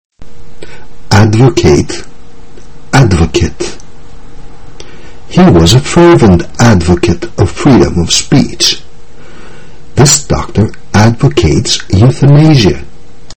Προσοχή όμως γιατί προφέρονται διαφορετικά το ρήμα από το ουσιαστικό.